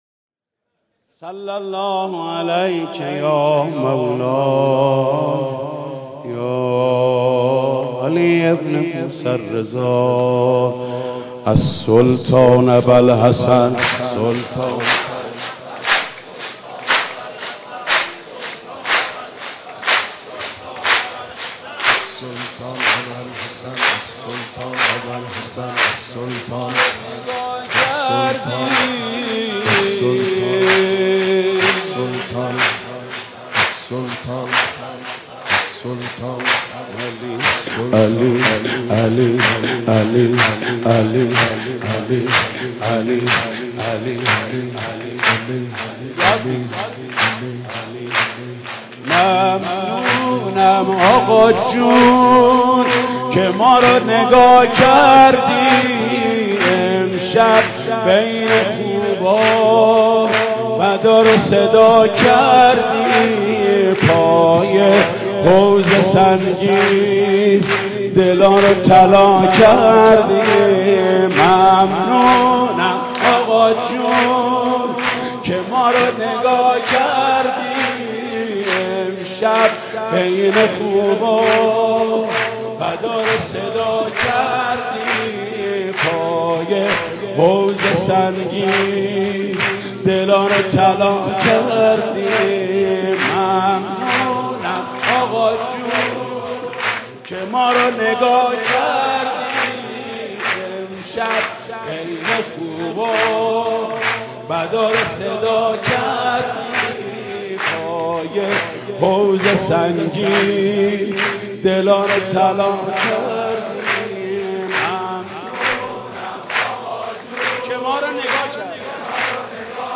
«میلاد امام رضا 1393» سرود: ممنونم آقا جون که ما رو نگاه کردی